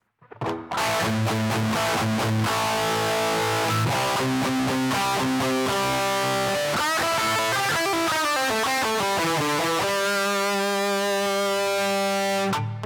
Ich bekomme immer das selbe Resultat: Der Distortion Sound klingt vollkommen übersteuert und unnatürlich verzerrt. Mein Setup ist folgendes: Gitarre -> HX Stomp -> Mischpult -> kleine 75 W PA -> Harley Benton 2x12 Gitarrenbox.
Wenn ich die Lautstärke des Eingangassignals reduziere, bekomme ich geneu den gleichen verzerrten Sound, nur leiser.